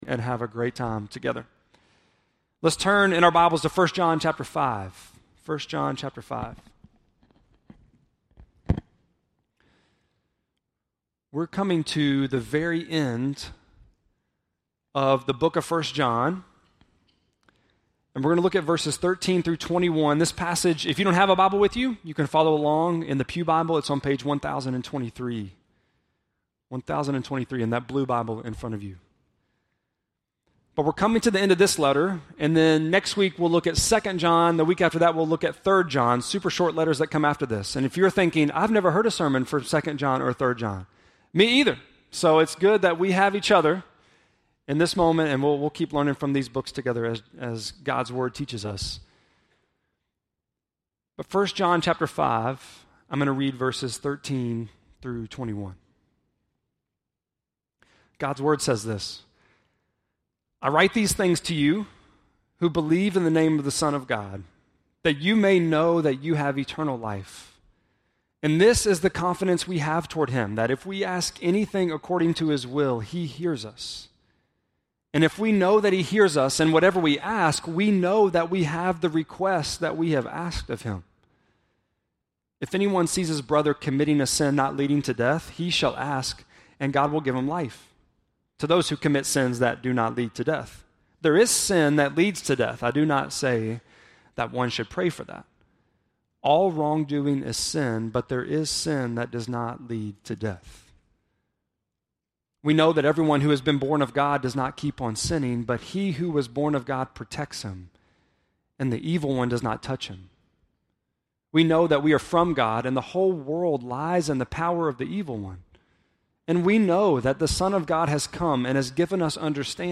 5.14-sermon.mp3